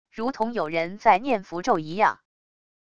如同有人在念符咒一样wav音频